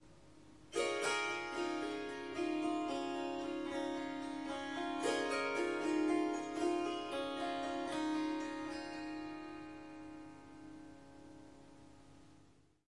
Swarmandal印度竖琴曲谱 " 竖琴弹奏曲谱5
这个奇妙的乐器是Swarmandal和Tampura的结合。
它被调到C sharp，但我已经将第四个音符（F sharp）从音阶中删除了。
这些片段取自三天不同的录音，因此您可能会发现音量和背景噪音略有差异。一些录音有一些环境噪音（鸟鸣，风铃）。
Tag: 竖琴 弦乐 旋律 Swarsangam 民族 Swarsangam 印度 即兴重复段 Surmandal Swarmandal 旋律